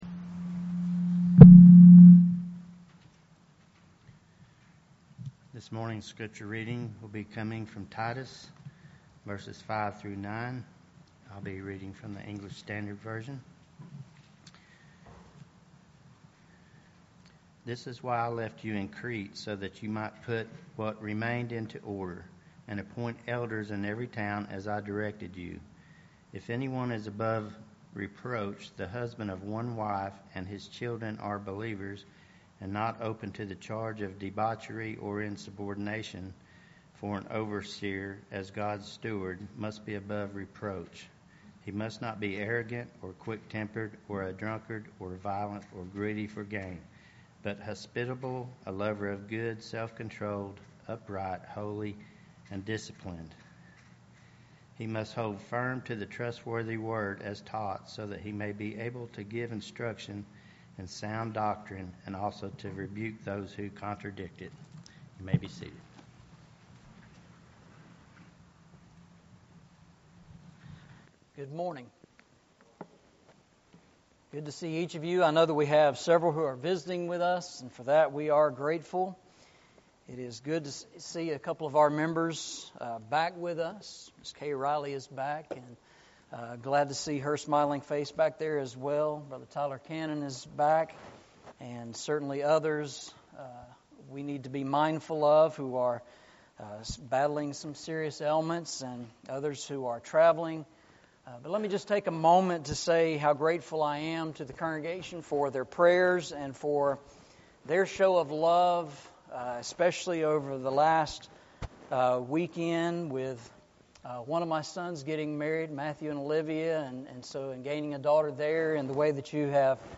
Eastside Sermons
Service Type: Sunday Morning